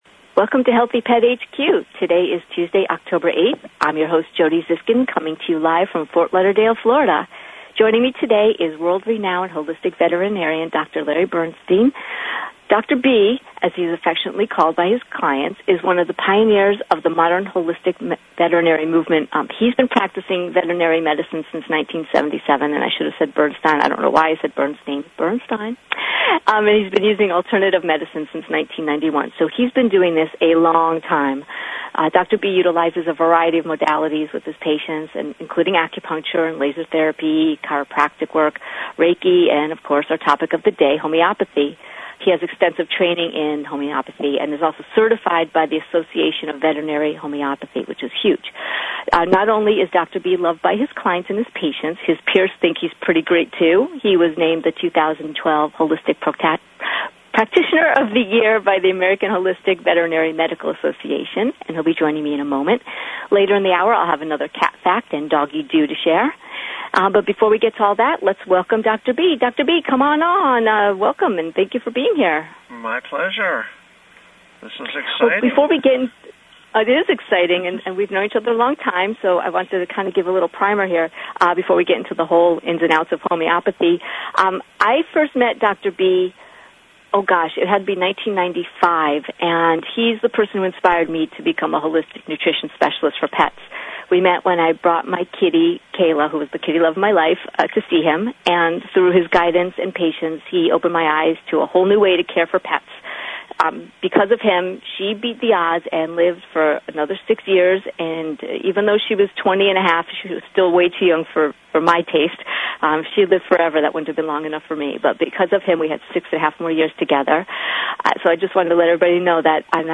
Radio Interview 10-8-13